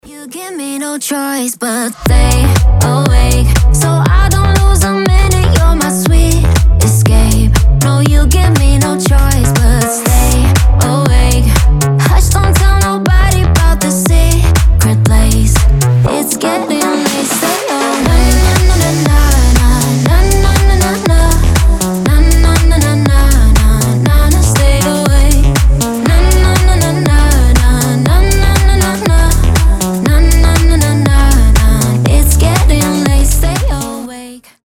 • Качество: 320, Stereo
Dance Pop